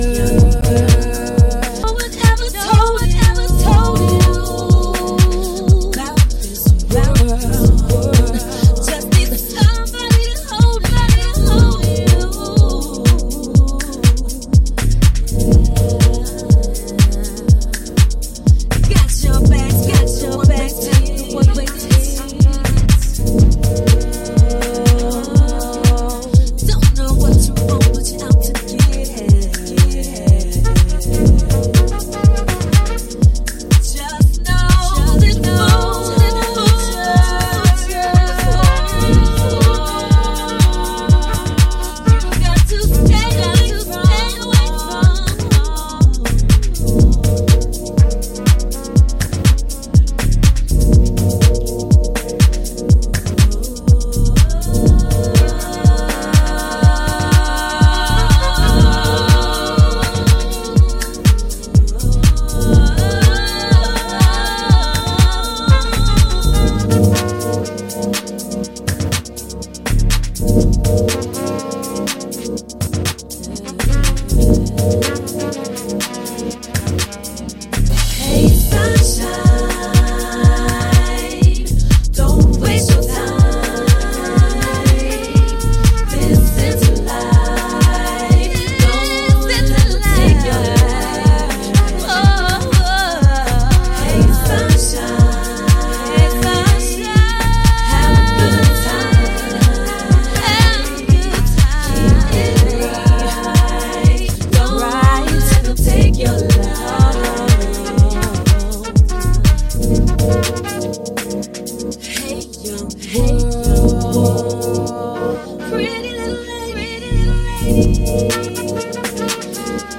sweet precautionary tune
House